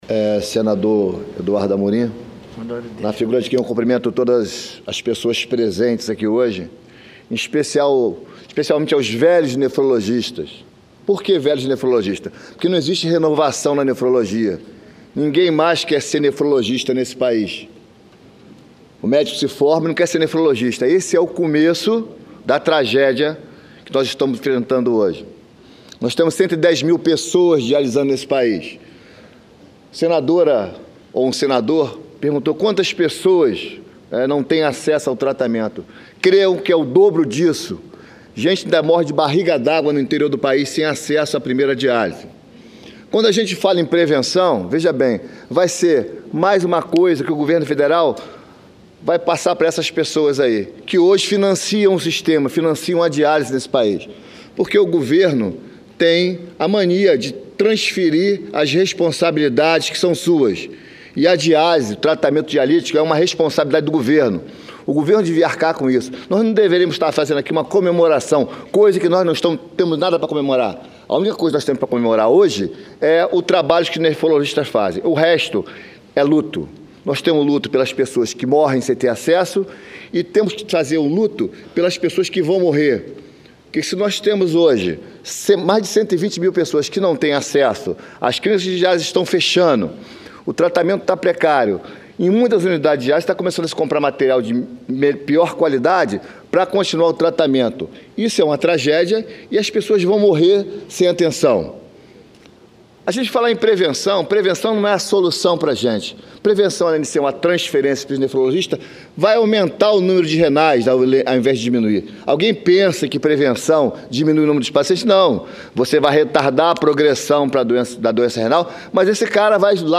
Sessão Especial
Pronunciamento